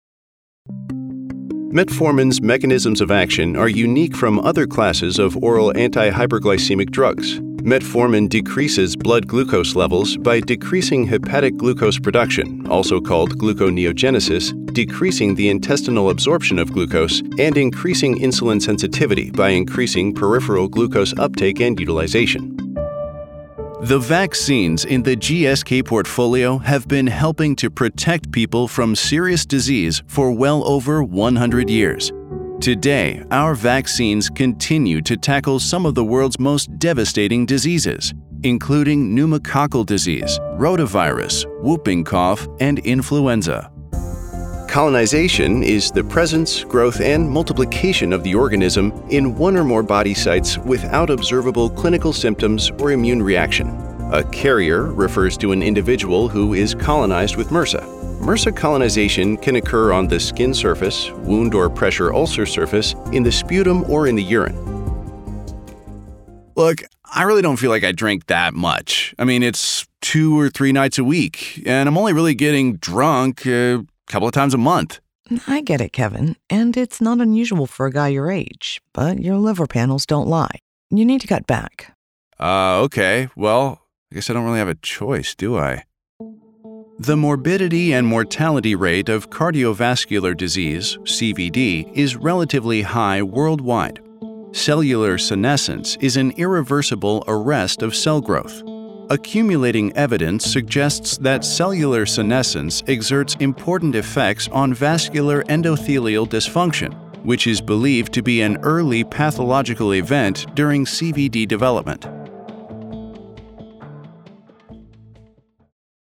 Medical Narration
His voice is authentic, bold, classy, friendly, genuine, professional, natrual and warm.